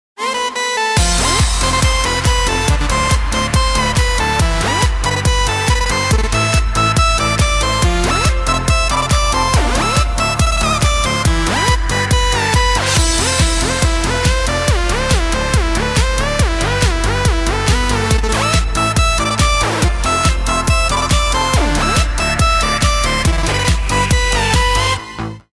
رینگتون پرنشاط و بیکلام